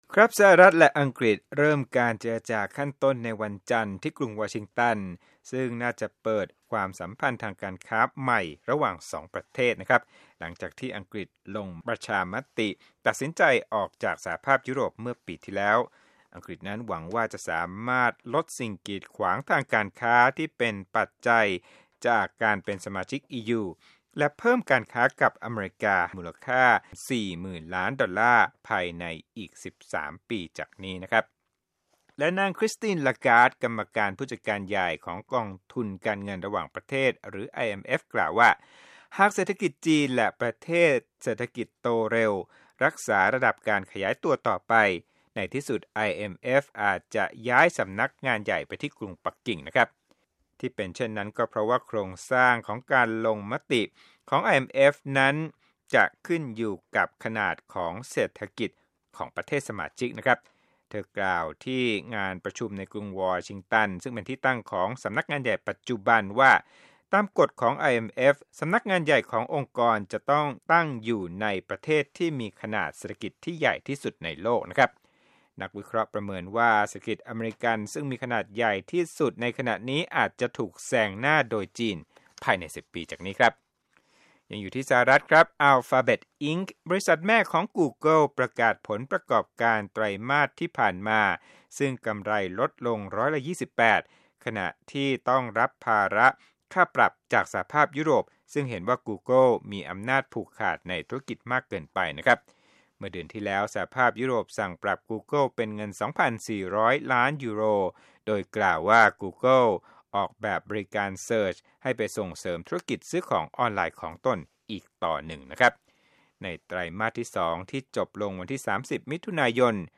ข่าวธุรกิจ 7/24/2017